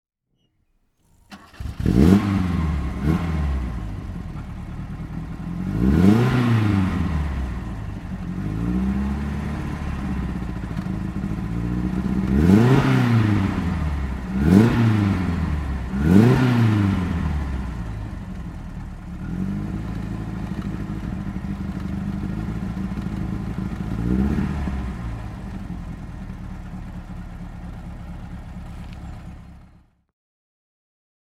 Motorsounds und Tonaufnahmen zu Opel Fahrzeugen (zufällige Auswahl)
Opel Kadett A Italsuisse Spider (1964) - Starten und Leerlaufgeräusch
Opel_Kadett_A_Italsuisse_Spider_1964.mp3